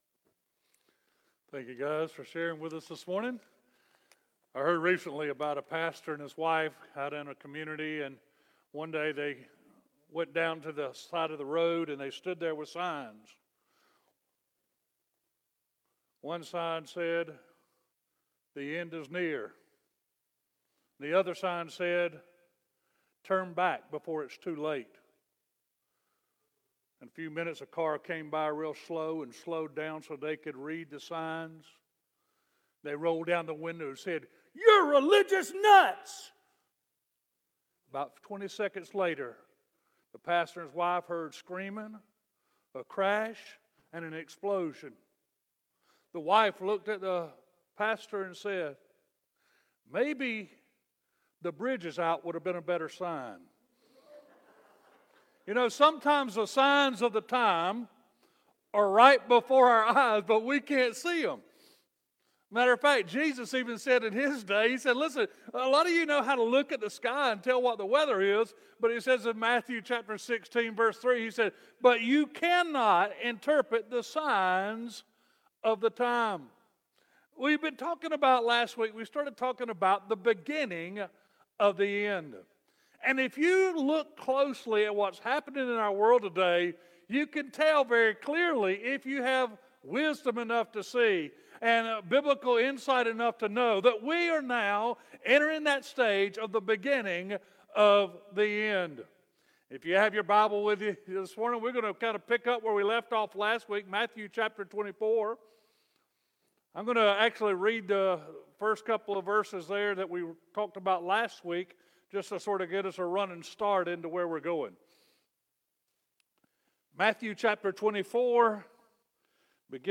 Sermons | Eastside Baptist Church
Guest Speaker